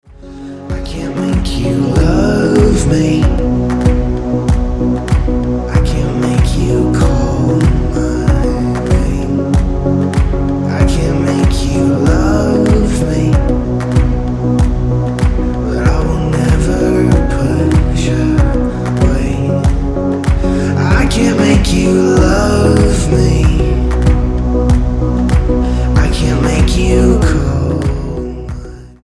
Style: Dance/Electronic